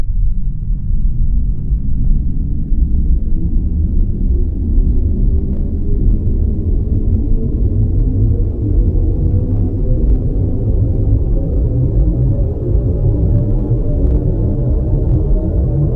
acceleration-old1.wav